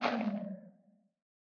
sculk_clicking_stop1.ogg